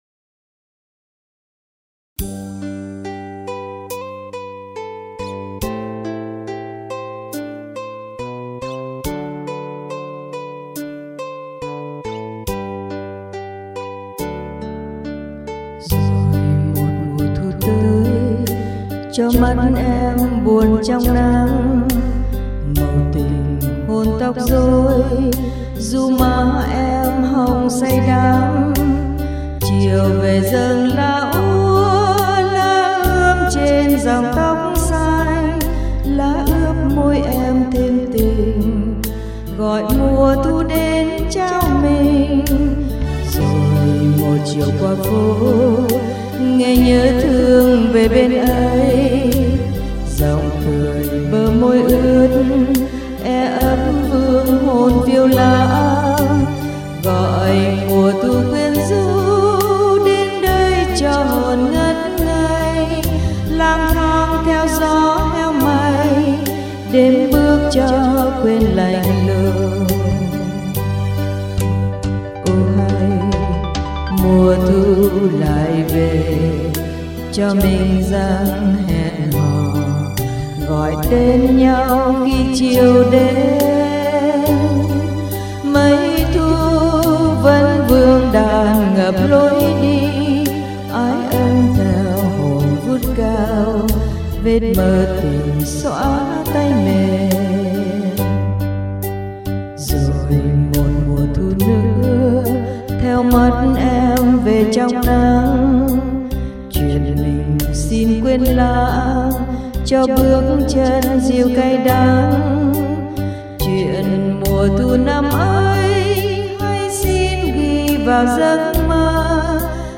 Nhạc đệm